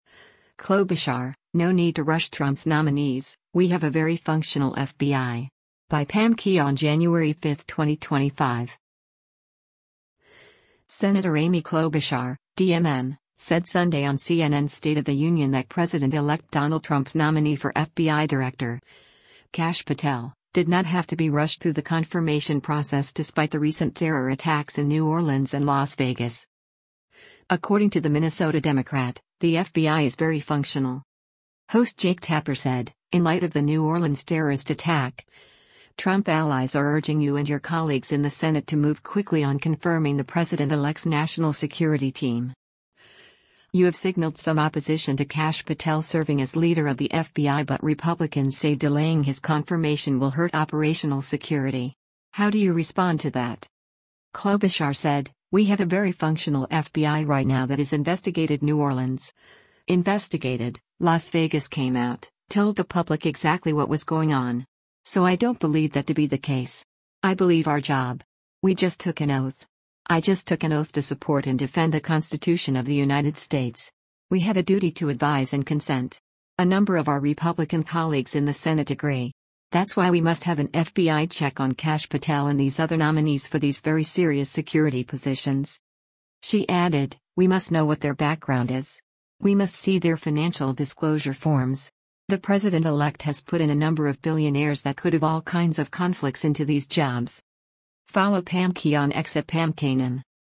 Senator Amy Klobuchar (D-MN) said Sunday on CNN’s “State of the Union” that President-elect Donald Trump’s nominee for FBI director, Kash Patel, did not have to be rushed through the confirmation process despite the recent terror attacks in New Orleans and Las Vegas.